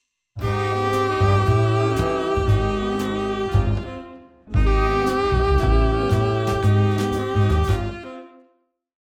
The key change from Bb to E to Bb is the most obscure.
It’s easiest to hear and understand if you hold the common tone “A” over all three chords like this.
bb-to-b7-to-bb.mp3